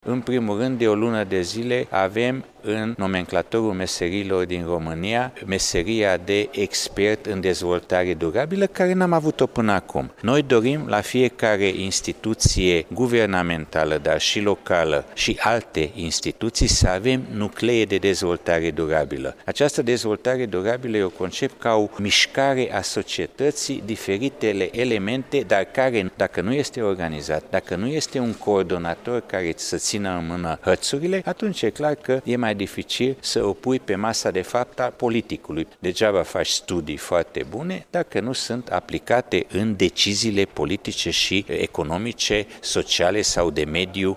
Coordonatorul departamentului de stat pentru dezvoltare durabilă din România, Laszlo Borbely, consilier de stat în cadrul Guvernului, prezent la Iaşi, a precizat că în urma consfătuirilor avute în ultimele luni în ţară, s-a luat decizia înfiinţării unor nuclee de dezvoltare durabilă în cadrul instituţiilor guvernamentale dar şi a celor locale.